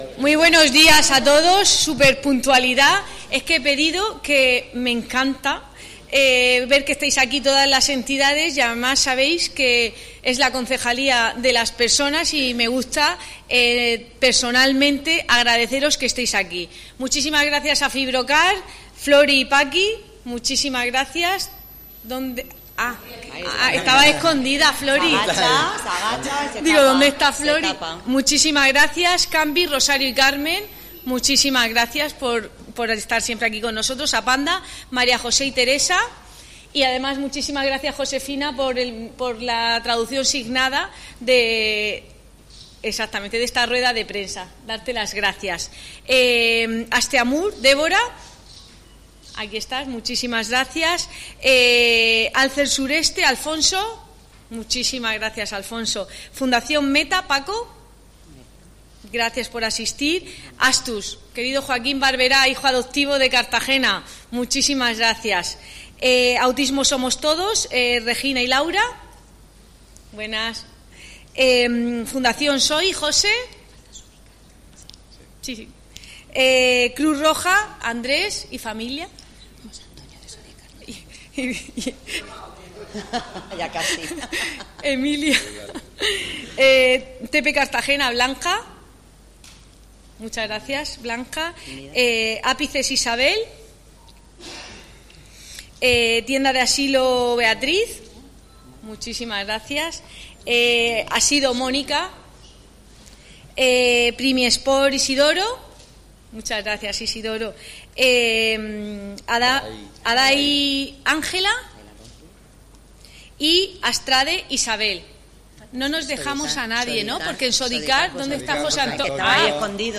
La programación de enero a junio ha sido presentada por la concejal de Política Social, Igualdad y Familia, Cristina Mora, este jueves 13 de febrero en el Palacio Consistorial.